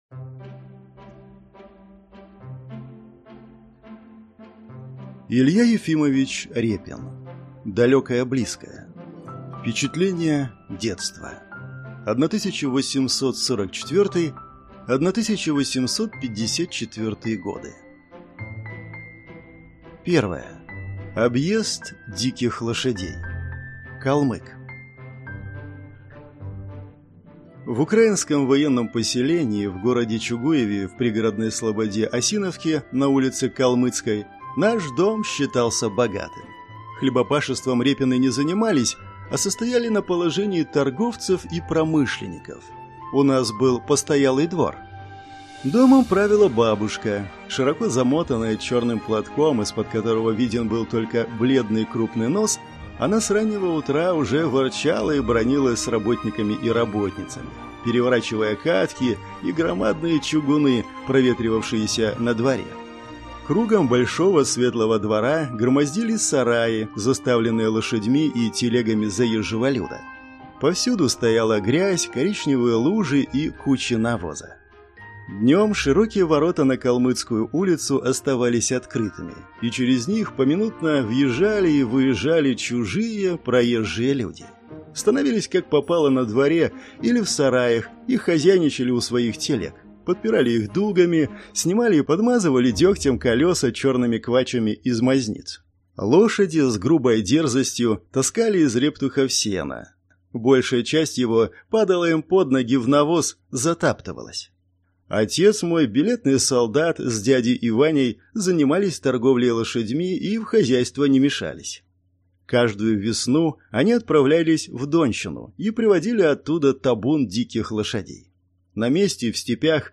Аудиокнига Далекое близкое | Библиотека аудиокниг